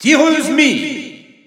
Announcer pronouncing Mii Gunner.
Mii_Gunner_French_Announcer_SSBU.wav